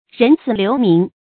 人死留名 rén sǐ liú míng
人死留名发音